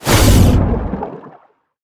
Sfx_creature_shadowleviathan_exoattack_loop_joystick_and_water_os_03.ogg